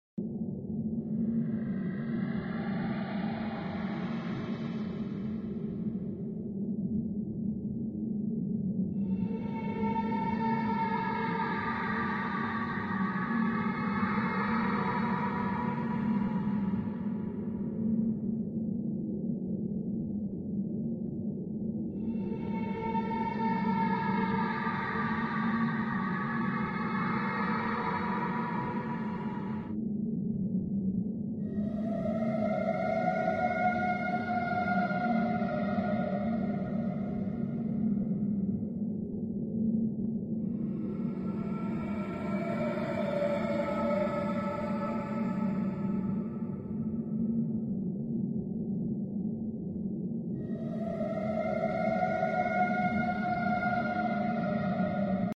horror sound ID ROBLOX AUDIO sound effects free download